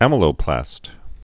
(ămə-lō-plăst)